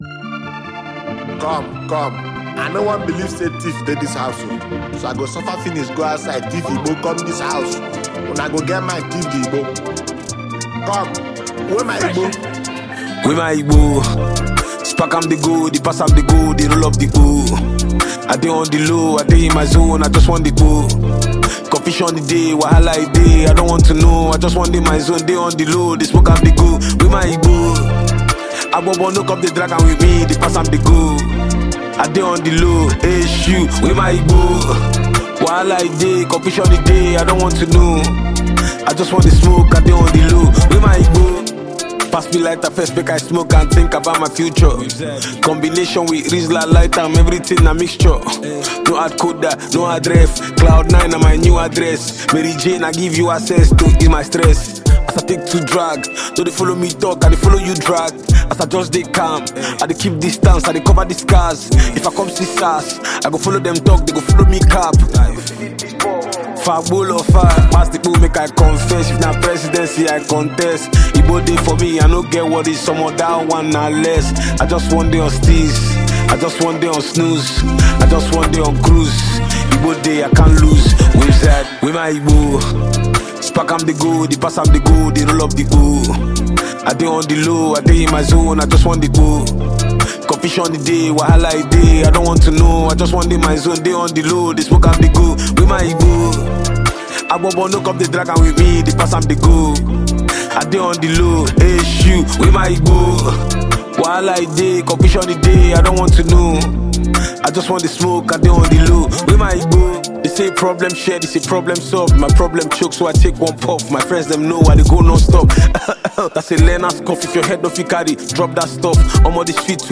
Nigerian hip-hop sensation and popular freestyler